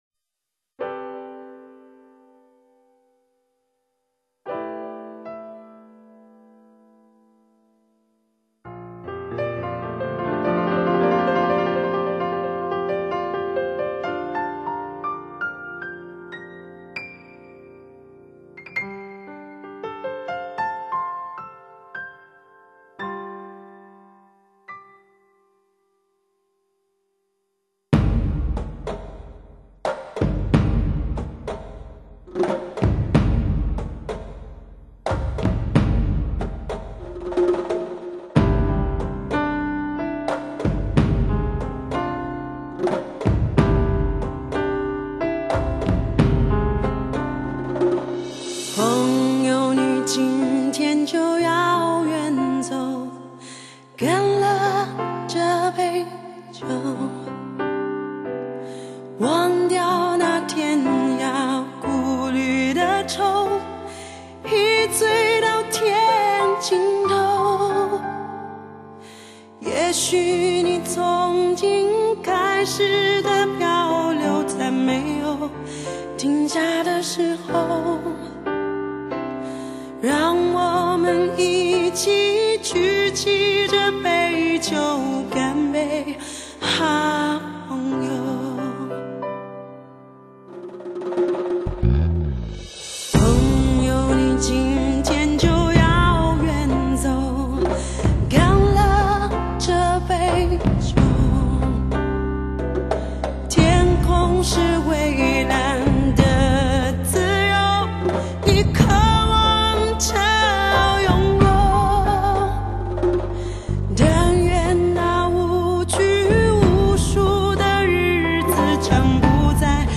虽然歌是旧的，但录音很好，唱得也不错，另有一番风味．．．．